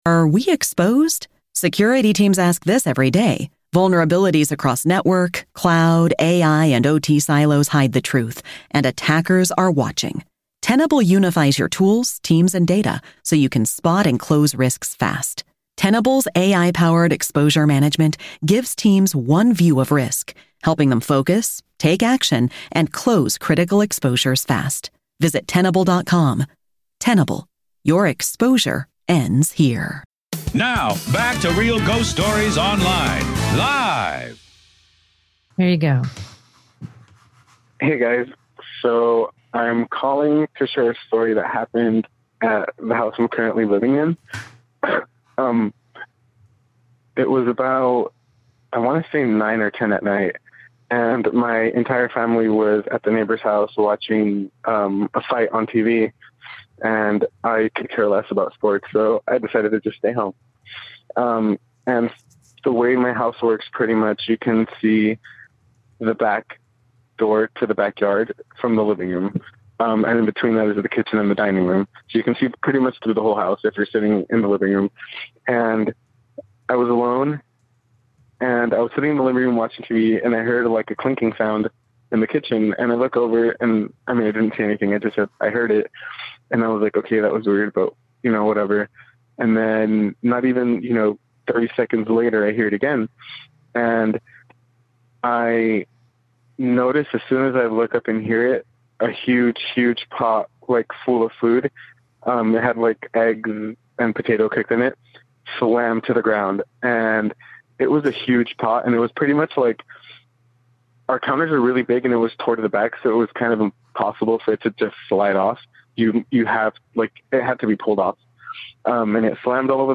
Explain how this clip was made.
Real Ghost Stories LIVE!